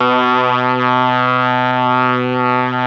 RED.BRASS  5.wav